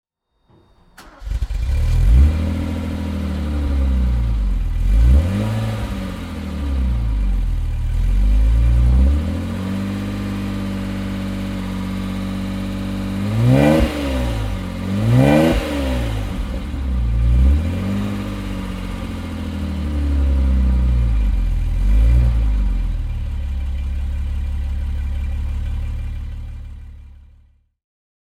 Mercedes-Benz 250 SE Coupé (1965) - Starten und Leerlauf